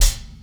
Wu-RZA-Hat 75.wav